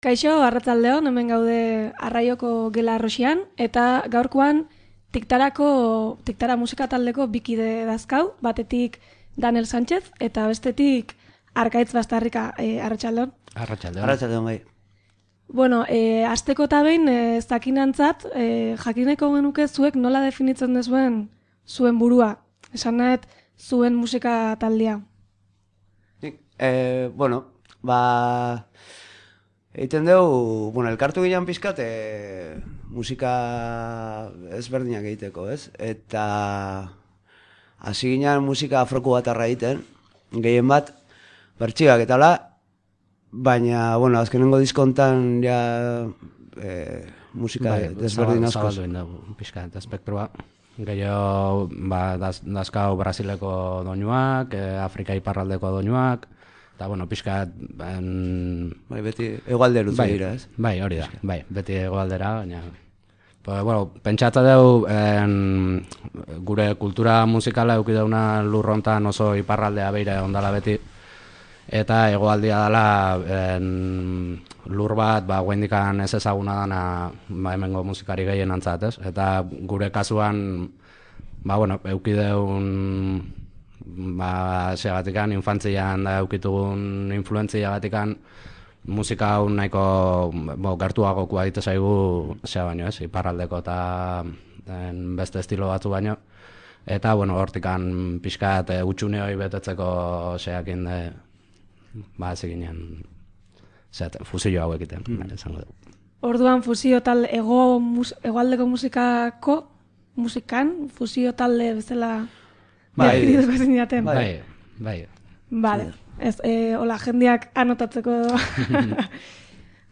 TikTara musika taldeko kideei elkarrizketa, Arraioko lagunei esker